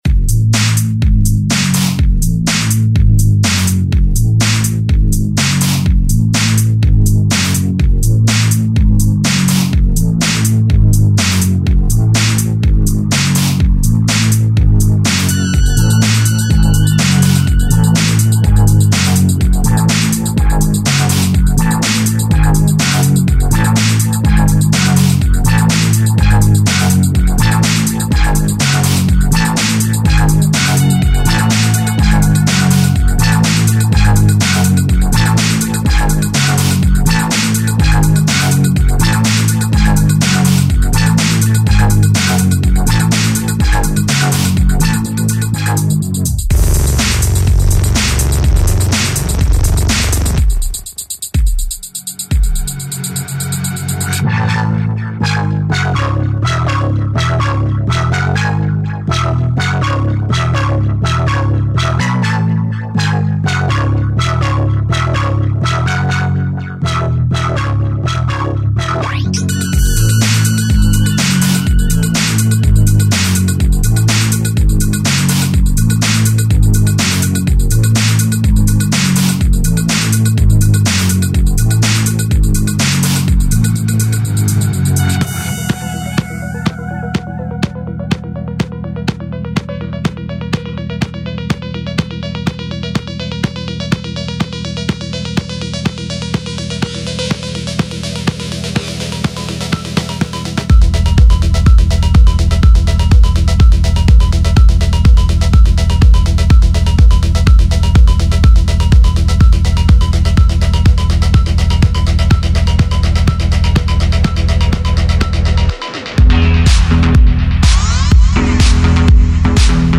Diese Sammlung von Loops, One-Shots und MIDI-Files bietet eine breite Palette von Techno Samples, Sounds und Melodien.